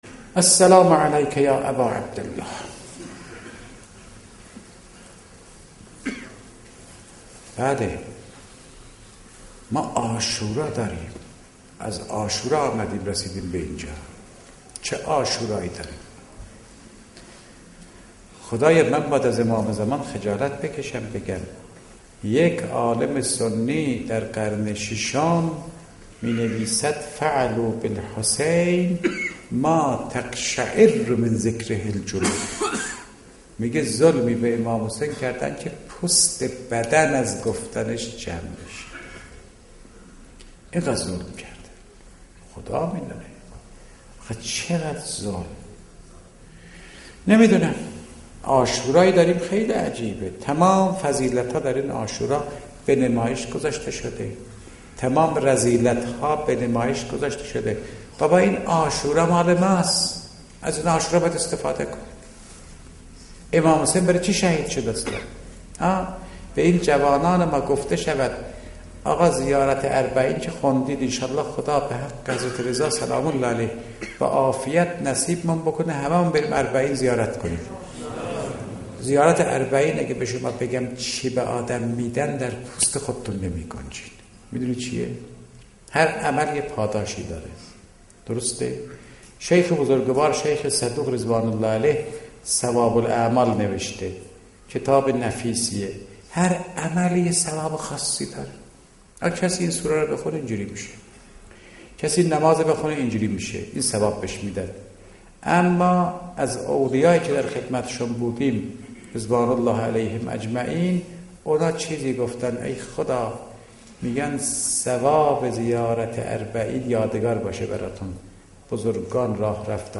روضه امام سجاد - 2